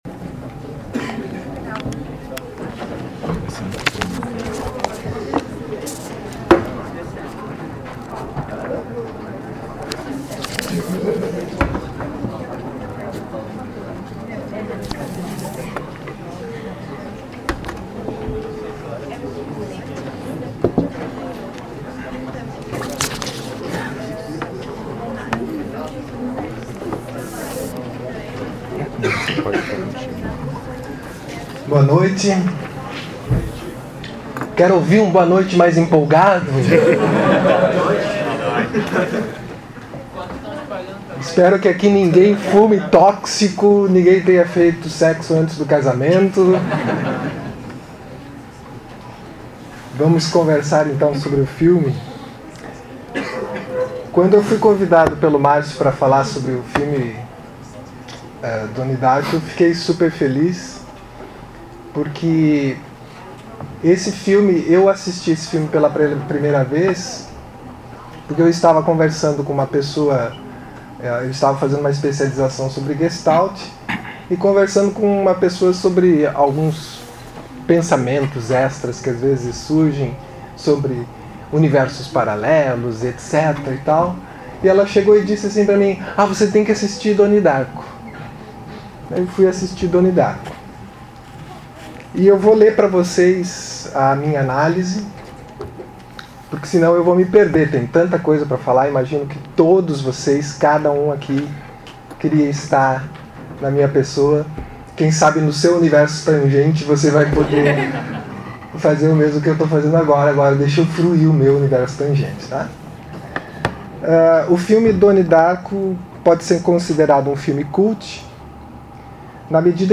Nesta seção você encontrará os áudios dos comentários realizados sobre cada um dos filmes apresentados nas edições do Projeto Cinema Mundo.